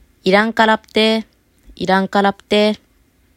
イランカラㇷ゚テ　　irankarapte　　こんにちは
これは、この ㇷ゚ が、日本語の プ とは音が違うので、わざと小文字にしているのです。